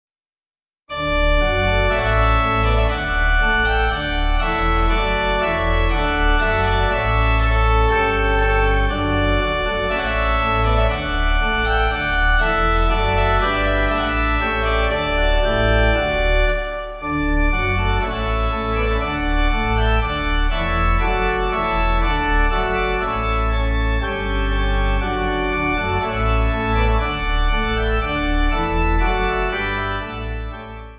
Easy Listening   1/D